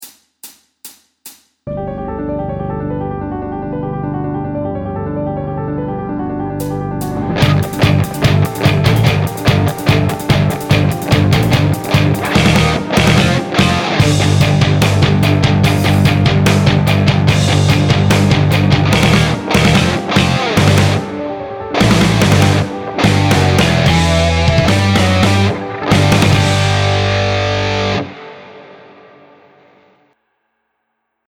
Downloads Download Pull Off Power Etude.pdf Download Pull Off Power .mp3 Download Pull Off Power Backing Track.mp3 Content You're gonna love this tasty G Lydian pull off lick.
Throughout this shred guitar exercise, I use downstrokes to pick the fretted note. Then, I pull off to the open string without picking.
This pull off exercise utilizes pedal tone technique. Starting in the G lydian mode, before modulating to a G minor B section.